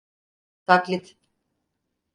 Pronúnciase como (IPA) /tak.lit/